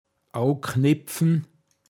pinzgauer mundart
abknöpfen, jemand etwas abnehmen (Geld) åoknepfn